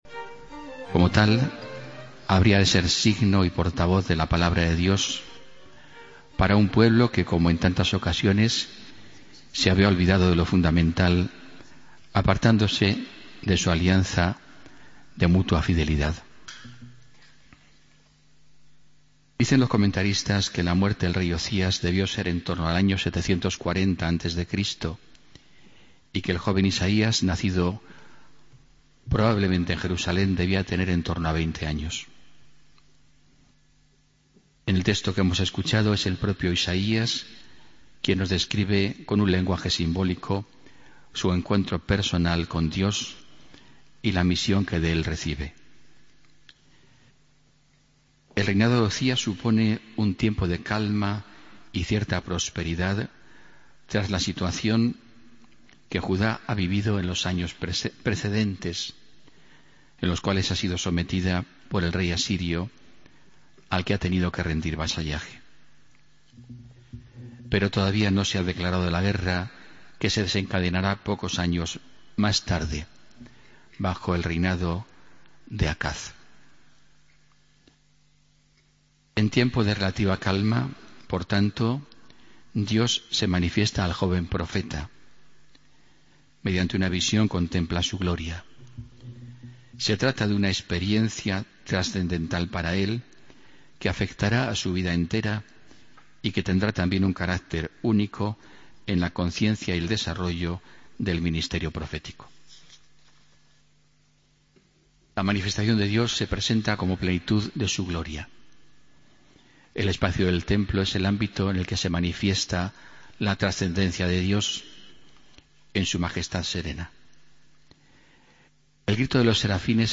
Homilía del domingo 7 de febrero de 2016